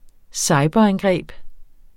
Udtale [ ˈsɑjbʌ- ]